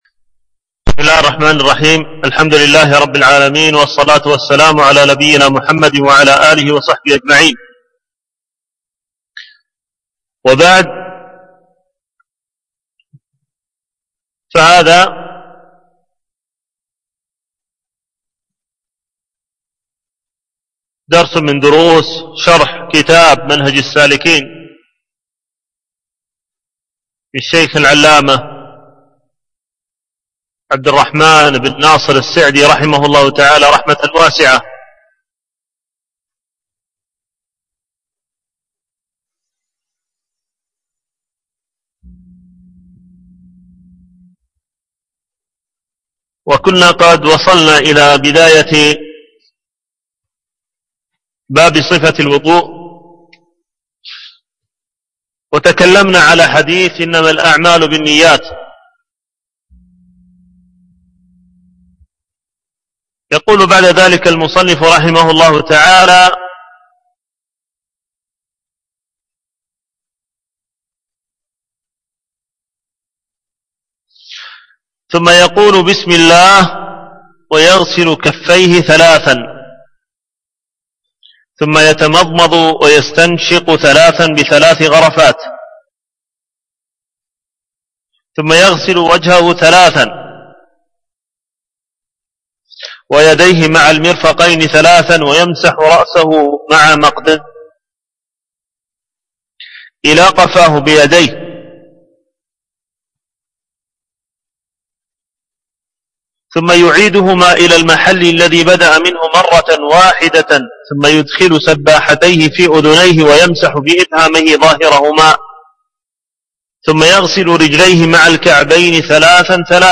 العنوان: الدرس التاسع الألبوم: شرح منهج السالكين وتوضيح الفقه فى الدين المدة